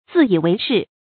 注音：ㄗㄧˋ ㄧˇ ㄨㄟˊ ㄕㄧˋ
自以為是的讀法